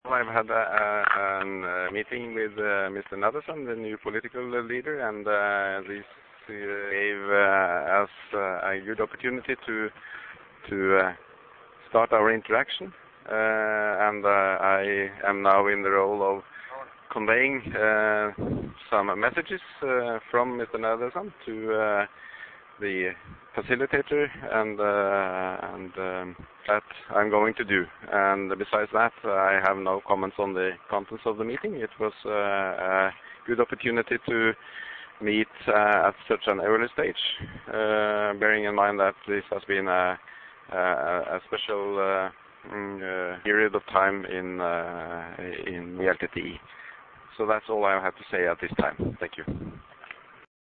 Maj. Gen. Solvberg addressing media in Ki'linochchi [Photo: TamilNet]
Voice: Press briefing by Johan Solvberg